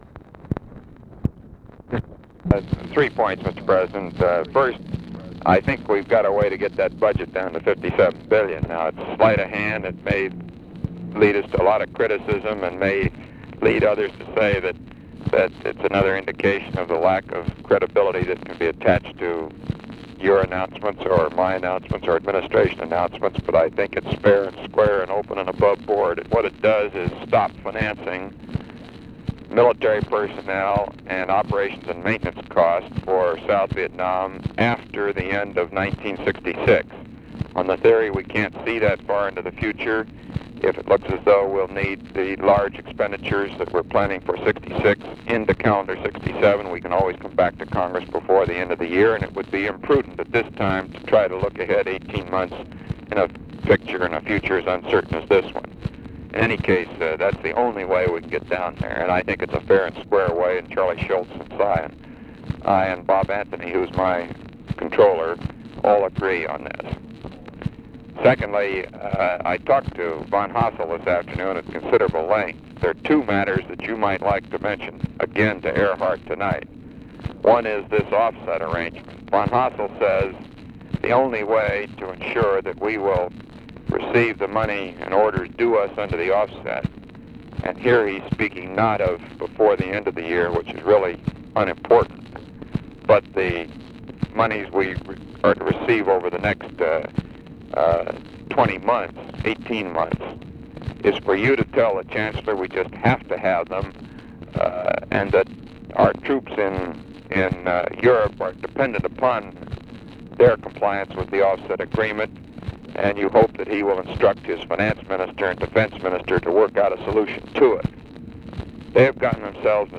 Conversation with ROBERT MCNAMARA, December 20, 1965
Secret White House Tapes